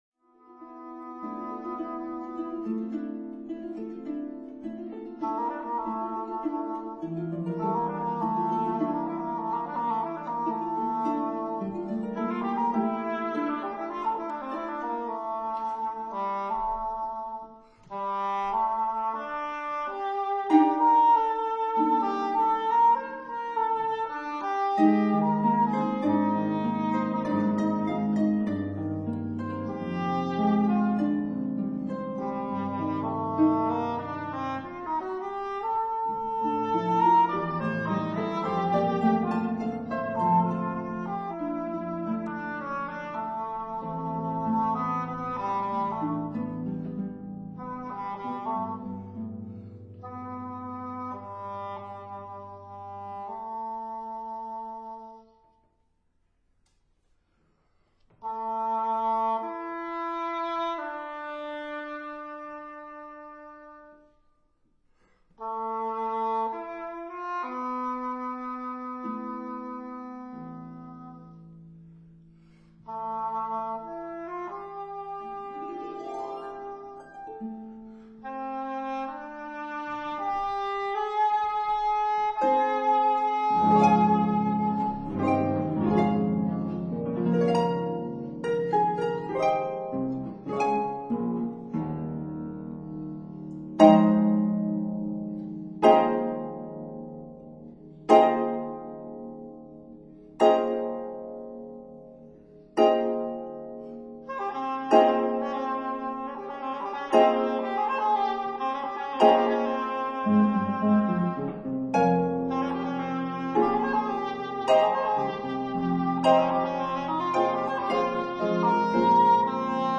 mit Englisch Horn